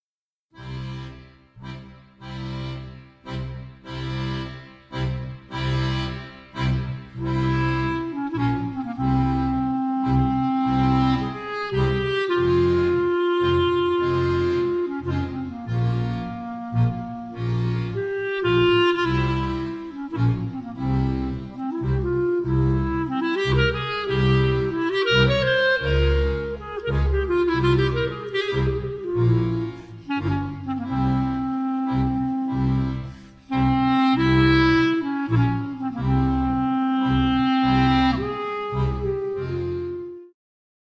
recital piosenek jidysz
śpiew, gitara
klarnet, tarogato
skrzypce
wiolonczela
kontrabas
akordeon
skrzypce, mandolina